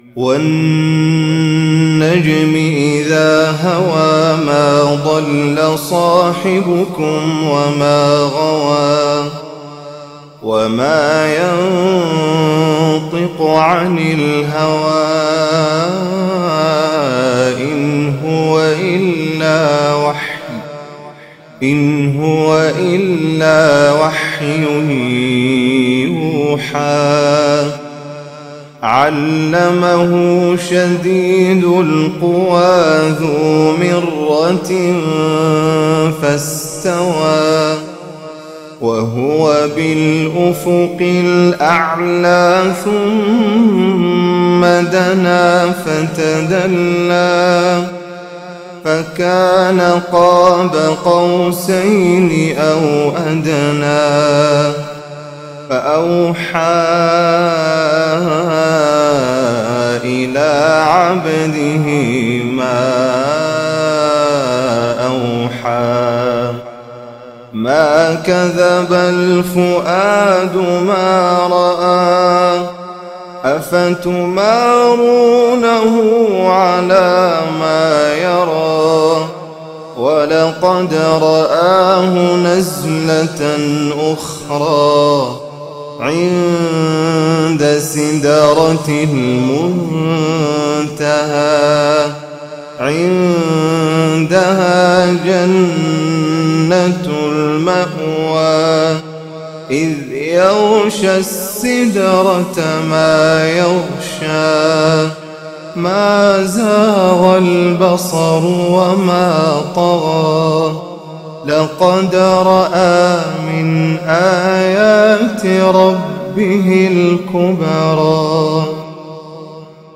تلاوة محبّرة متقنة من سورة النجم للقارئ
مسجد المتقين ، مكة المكرمة